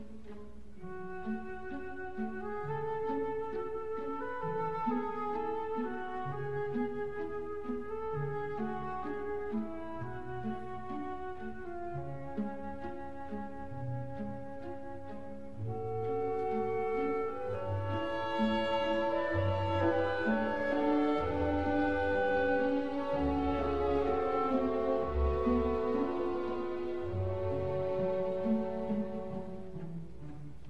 music doloroso